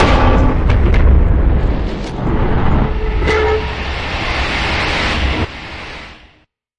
描述：从ZOOM H6录音机和麦克风Oktava MK01201领域录制的效果，然后处理。声音由几层组成，然后用不同的效果插件处理：CakeLab的Cakewalk。
标签： 爆炸 拖车 战争 混响 游戏 电影 设计 疯了 游戏 改造 电影 效果 金属 过渡 命中 视频 撞击 声音
声道立体声